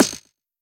hitForth_Far.wav